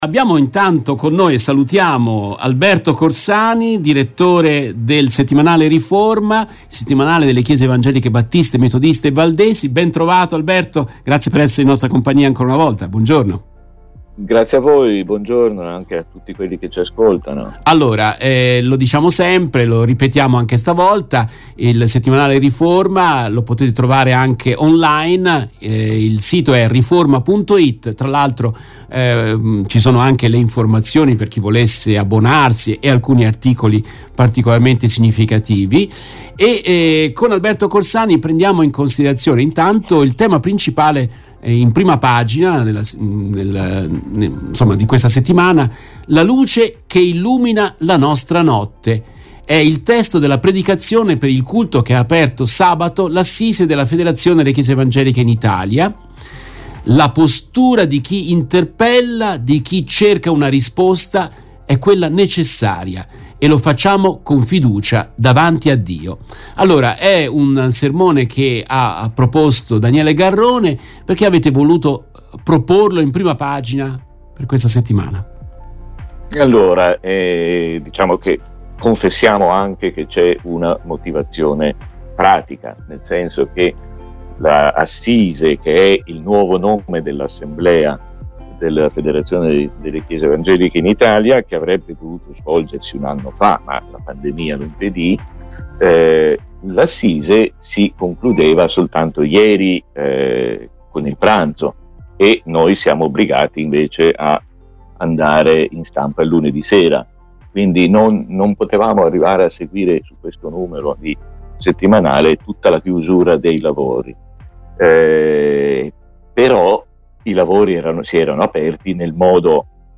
Oggi vi proponiamo un'intervista